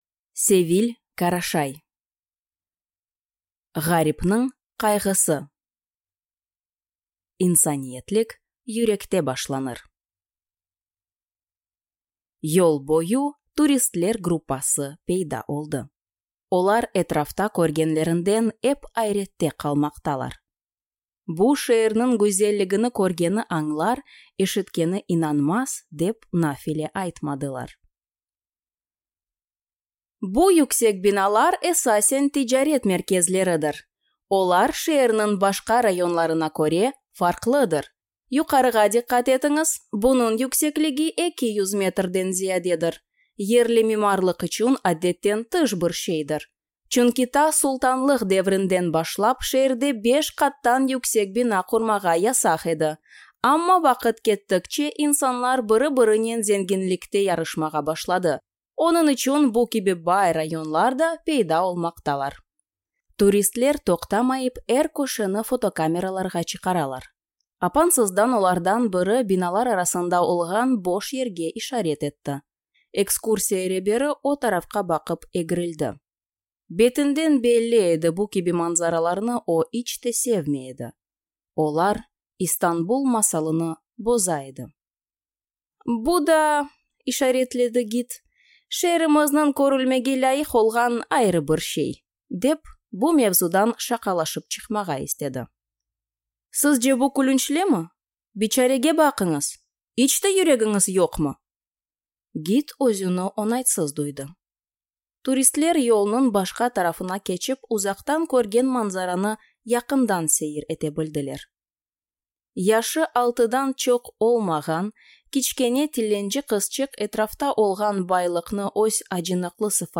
Аудиокнига Гъарипнинъ къайгъысы | Библиотека аудиокниг